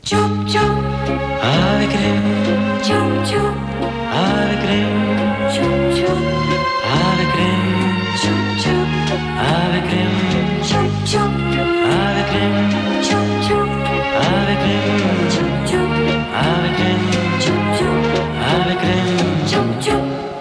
Publicitat cantada